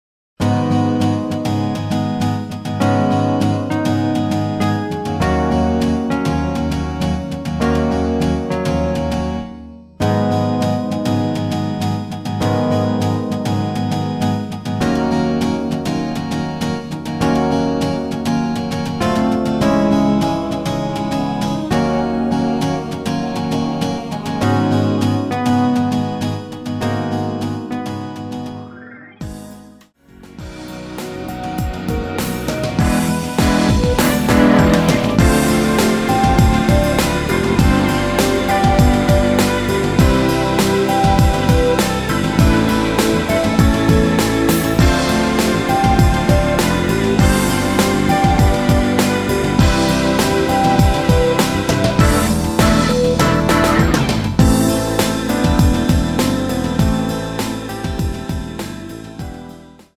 Abm
앞부분30초, 뒷부분30초씩 편집해서 올려 드리고 있습니다.
중간에 음이 끈어지고 다시 나오는 이유는